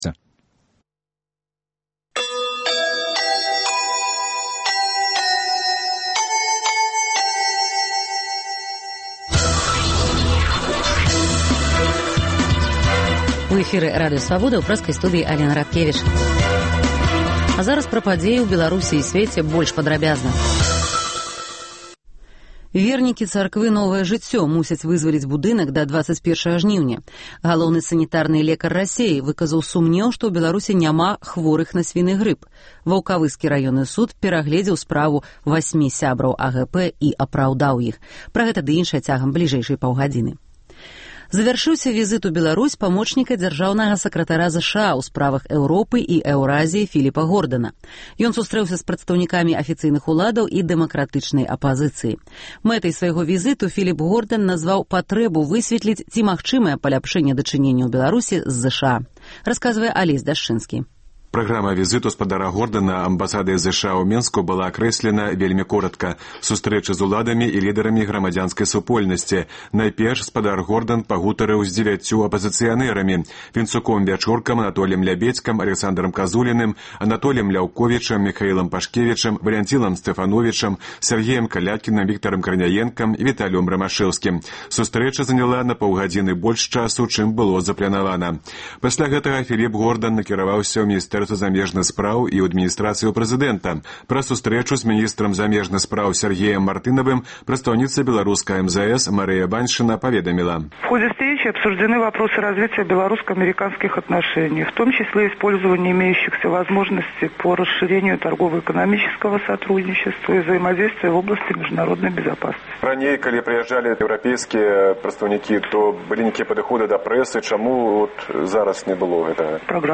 Паведамленьні нашых карэспандэнтаў, званкі слухачоў, апытаньні ў гарадах і мястэчках Беларусі.